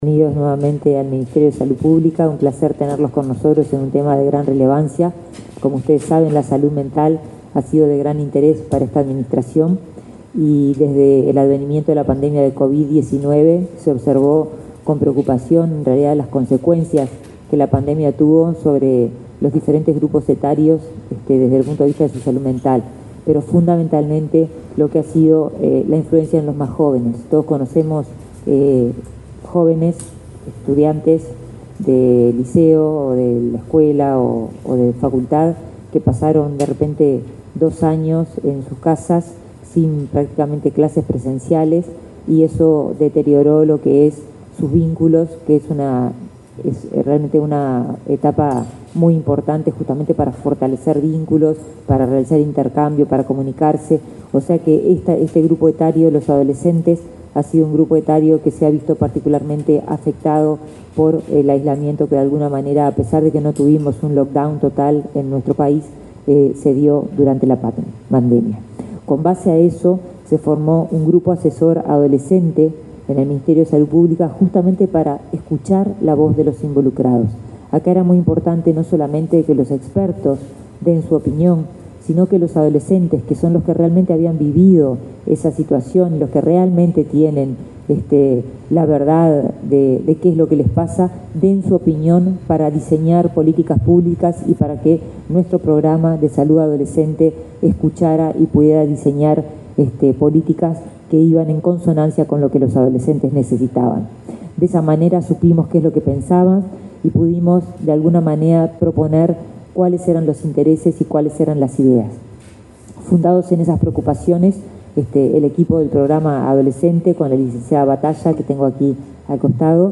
Palabras de la ministra de Salud Pública, Karina Rando
Este martes 19 en la sede del Ministerio de Salud Pública, la titular de esa cartera, Karina Rando participó en el acto de relanzamiento del curso